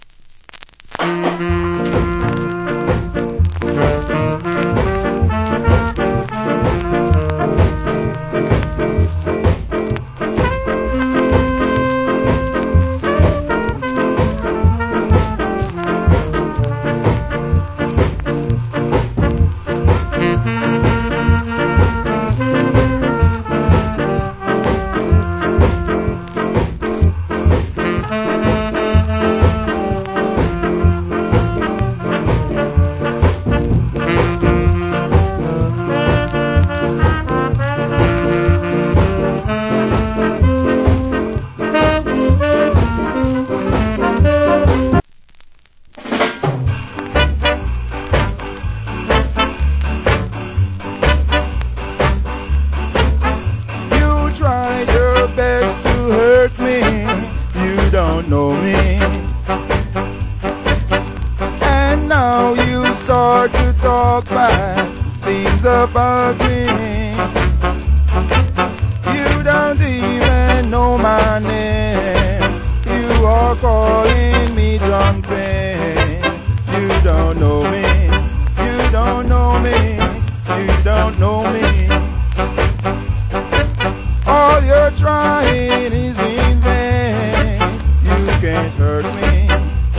Wicked Ska Inst.